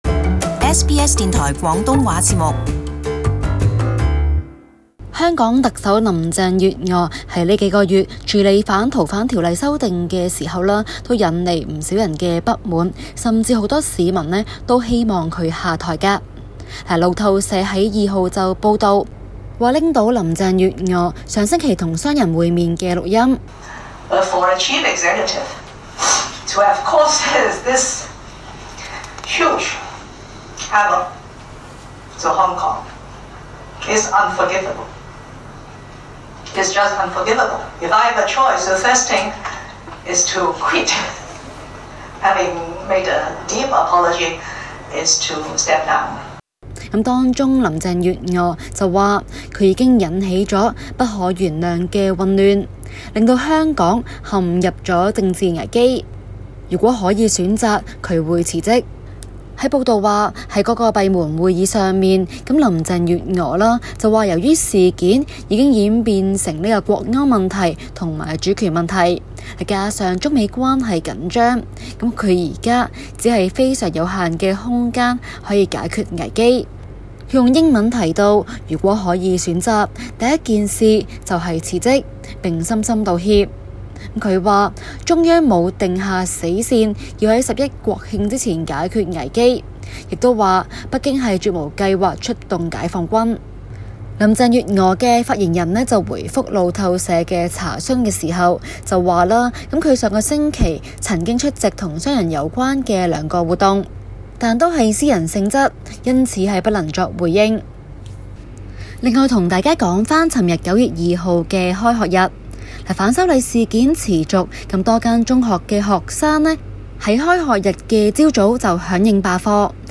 Leaked audio of Hong Kong leader Carrie Lam has emerged, in which can be heard saying she would quit her job if she had “a choice”.